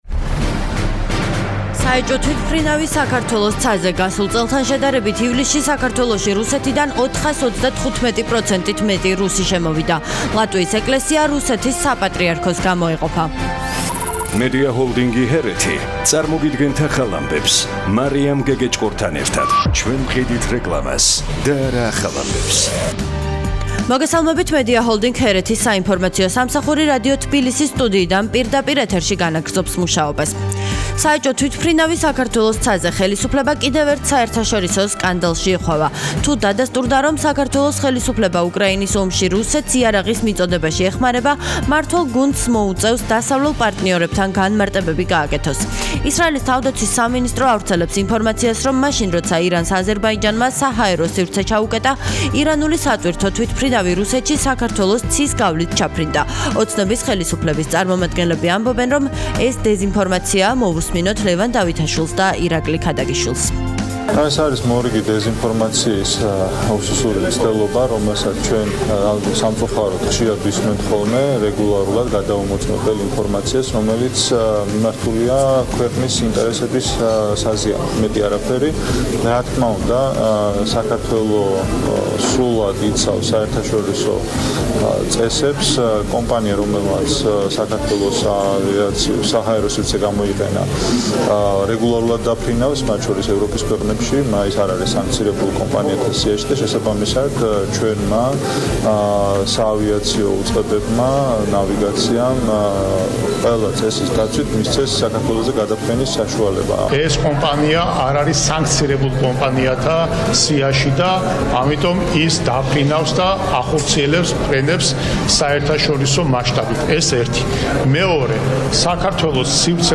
ახალი ამბები 14:00 საათზე - HeretiFM